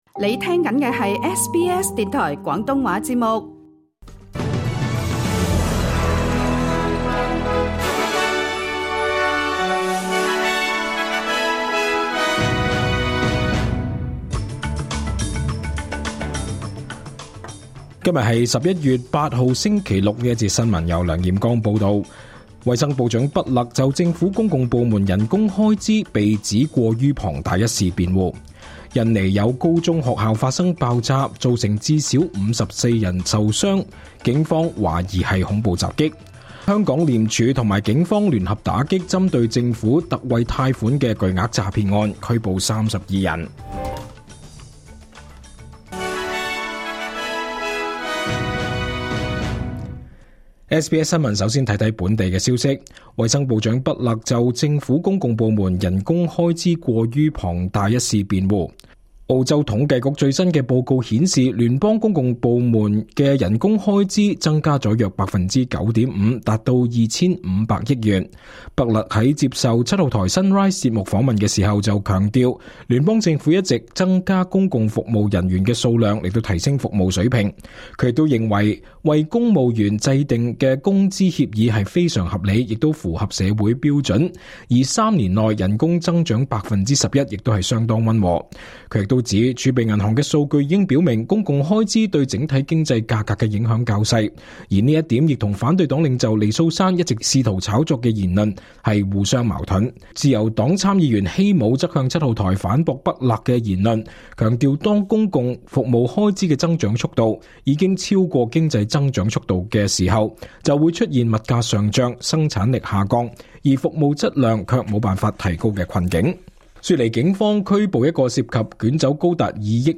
2025 年 11 月 9 日 SBS 廣東話節目詳盡早晨新聞報道。